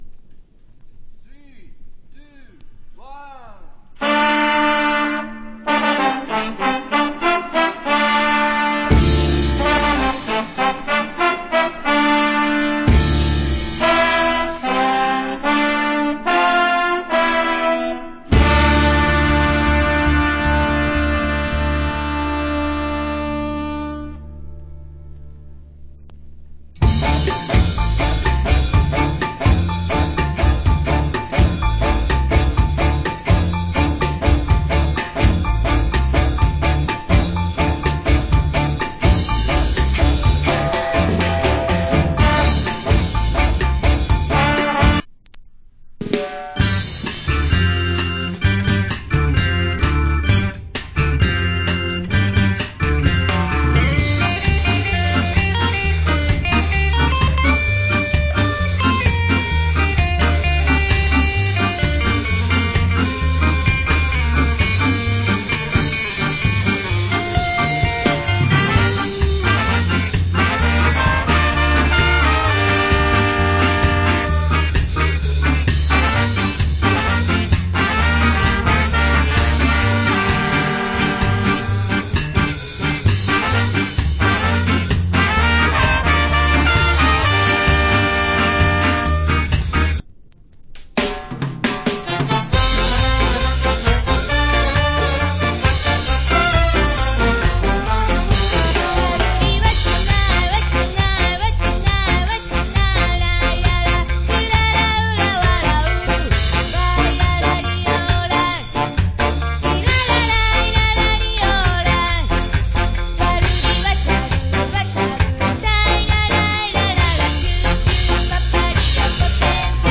Killer Ska Album.